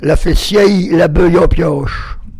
Localisation Île-d'Olonne (L')
Catégorie Locution